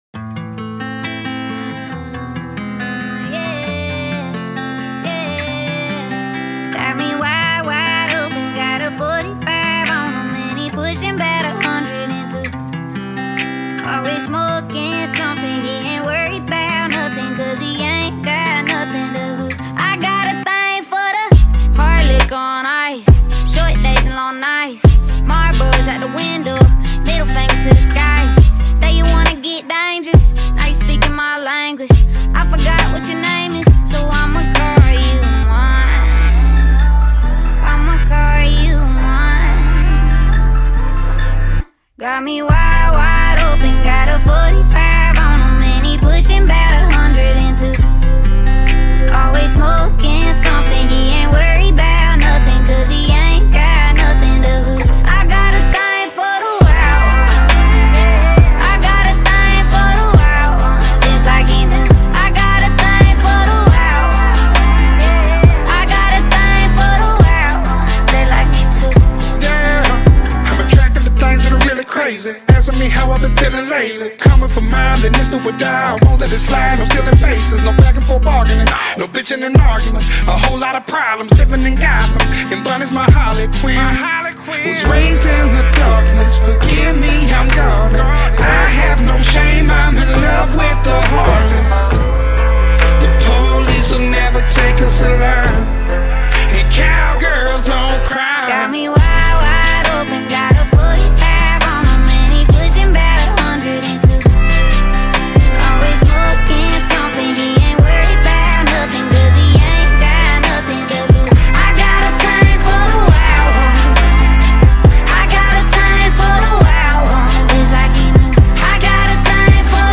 and exceptional vocal delivery for devoted listeners.